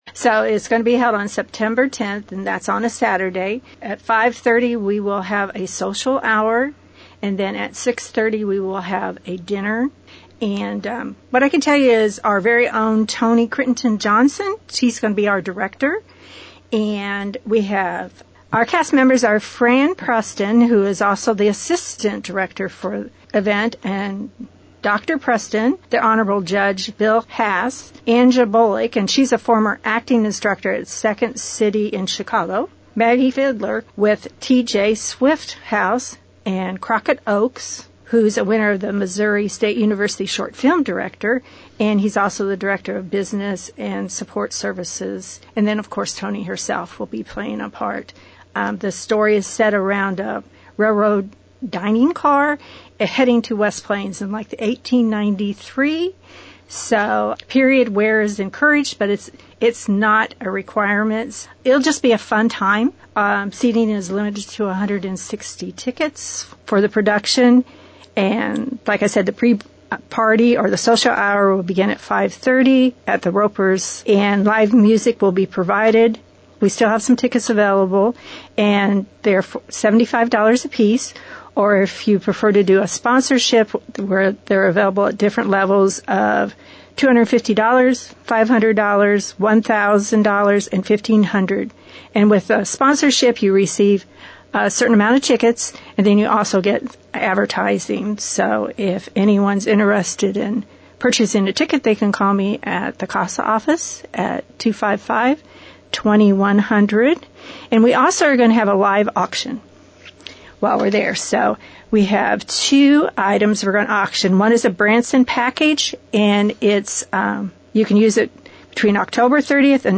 CASA-Emerald-Heist-Interview.mp3